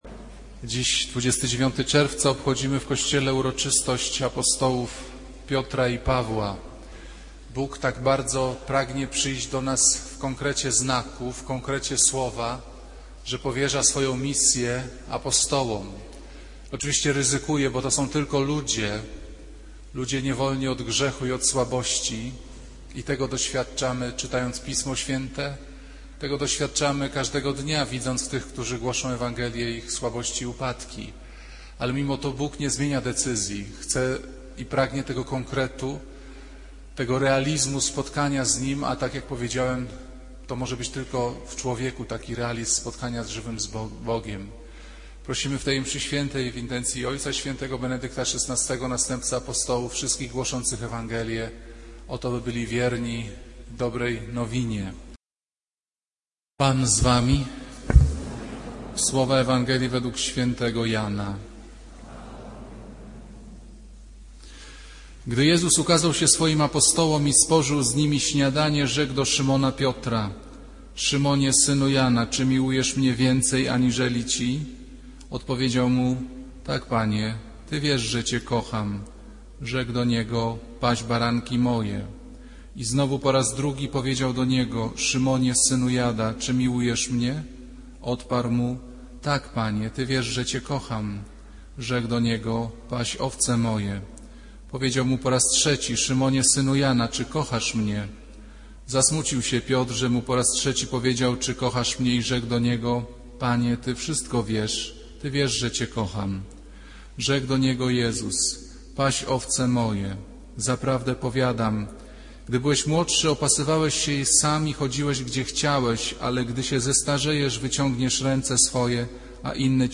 Kazanie z 29 czerwca 2008r.
niedziela, godzina 15:00, kościół św. Anny w Warszawie « Kazanie z 8 czerwca 2008r.